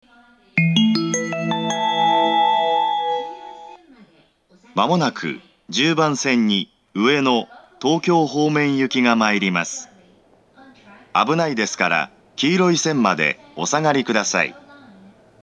１０番線接近放送
発車メロディー（せせらぎ）
９番線に比べると混雑時は余韻まで鳴りやすいです。
しかしながら収録環境が悪いのが厄介です。